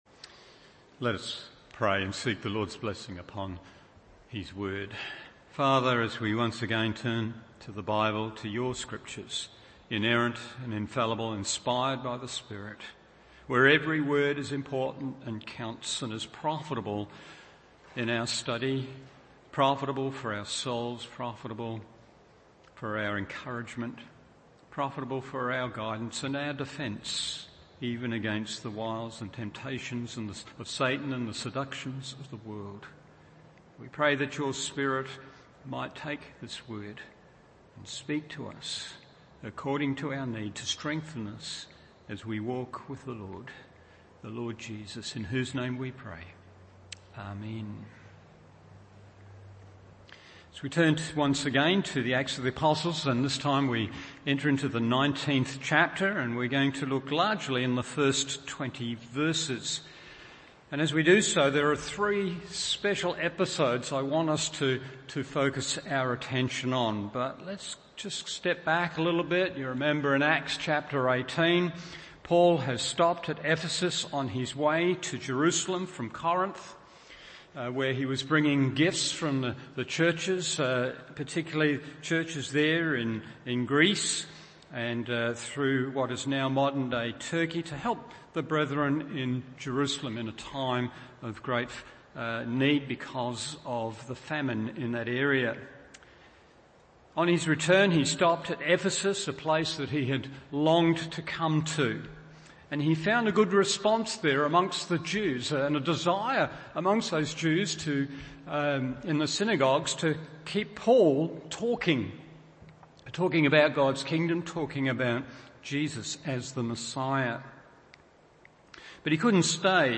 Evening Service Acts 19:1-20 1.